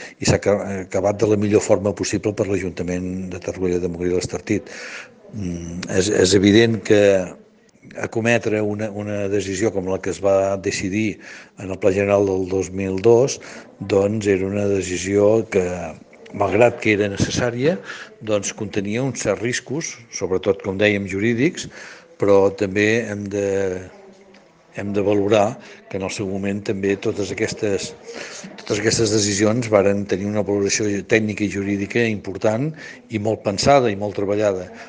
Jordi Colomí, alcalde de Torroella de Montgrí, ha expressat la seva satisfacció.